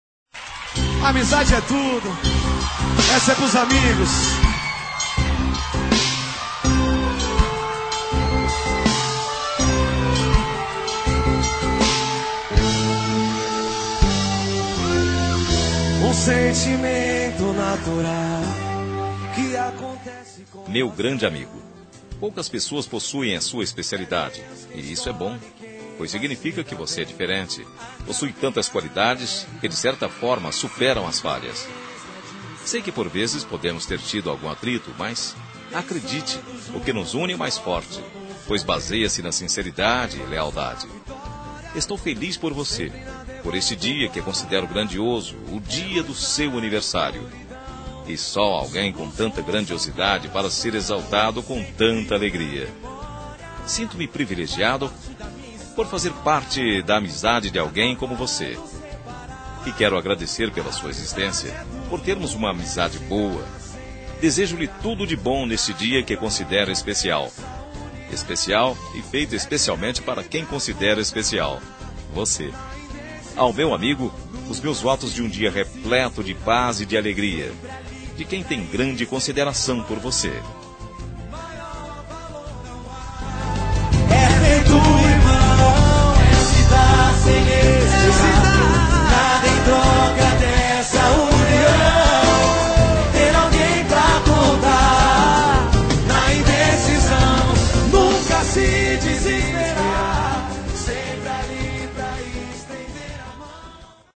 Telemensagem de Aniversário de Amigo – Voz Masculina – Cód: 1619